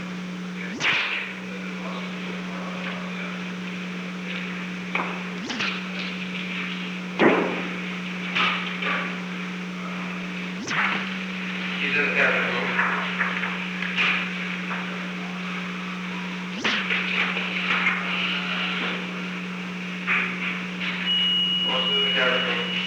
Participants: United States Secret Service agents Recording Device: Oval Office
On May 13, 1971, United States Secret Service agents met in the Oval Office of the White House at an unknown time between 12:20 pm and 12:50 pm. The Oval Office taping system captured this recording, which is known as Conversation 498-019 of the White House Tapes.